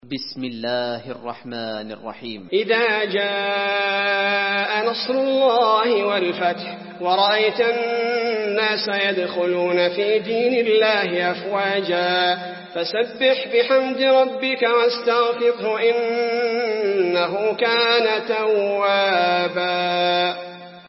المكان: المسجد النبوي النصر